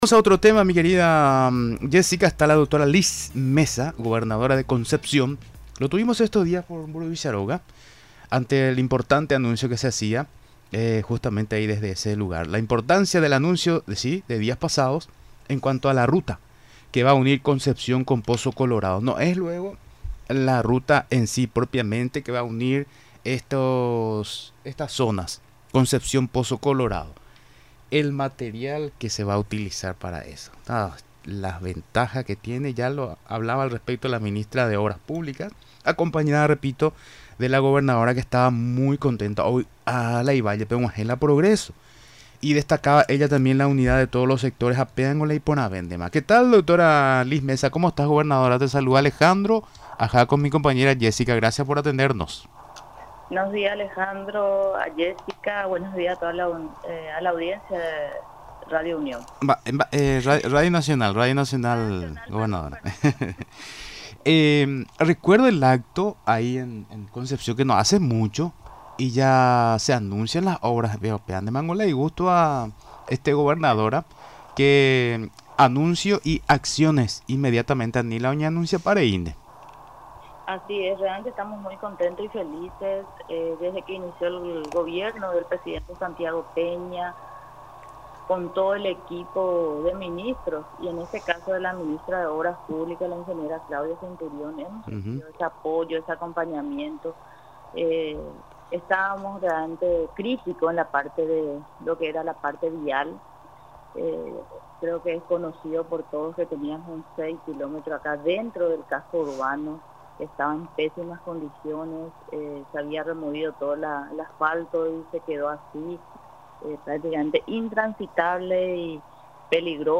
El primer departamento tendrá máquinas en pista ya desde enero, para la construcción de la primera ruta de hormigón del país, resaltó este lunes la gobernadora de Concepción, doctora Liz Meza.